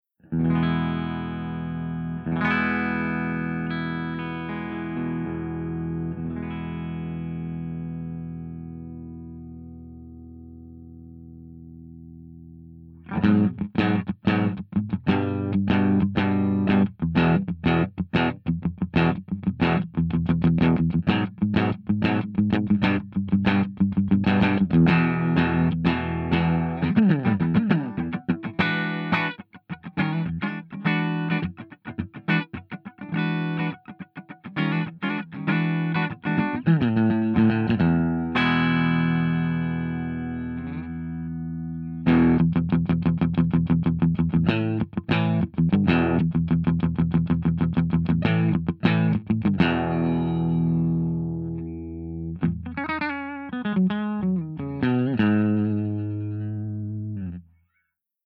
065_FENDERSUPERREVERB_STANDARD_SC.mp3